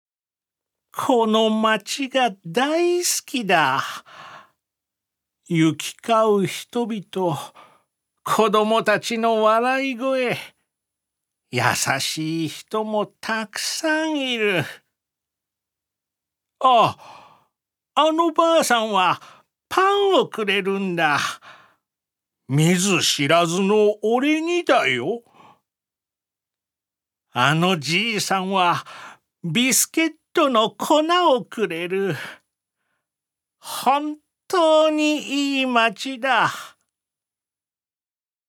所属：男性タレント
音声サンプル
セリフ２